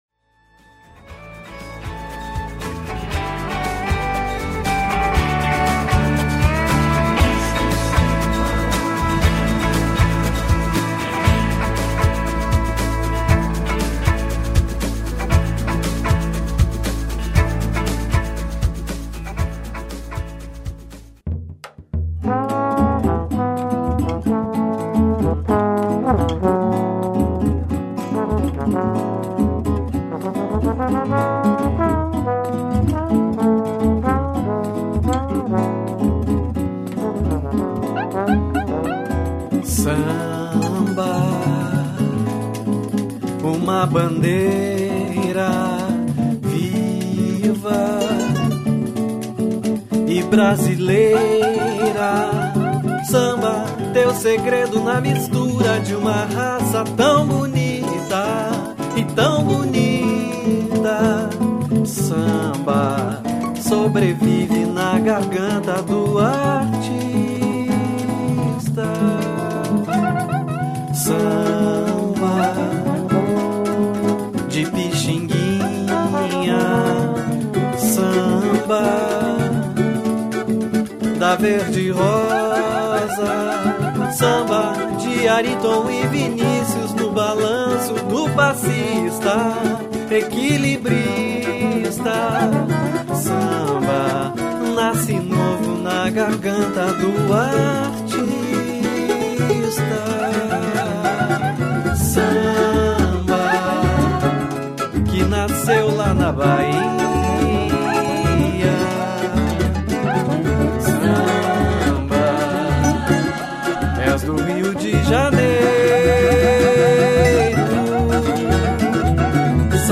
o delicioso blues